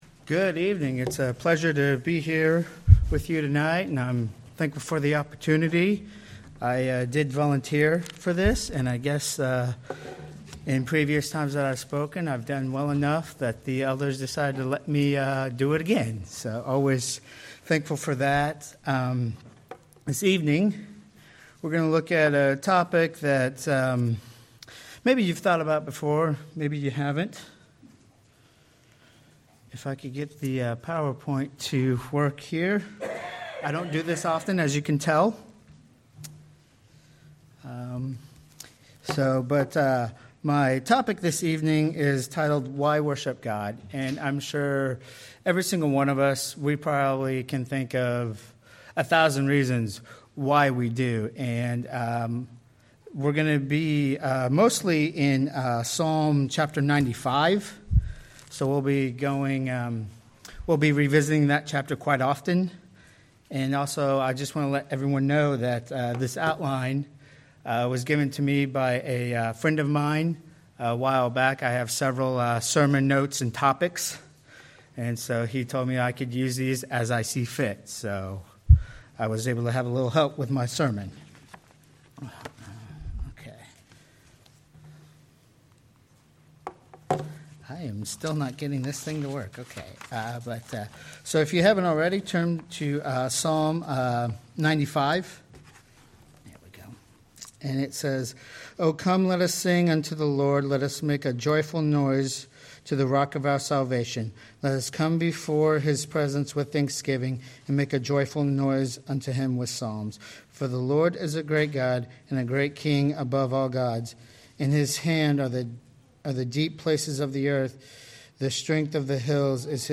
Series: Sermon Archives
Psalm 95 Service Type: Sunday Evening Worship We all can think of a thousand reasons why we should worship God.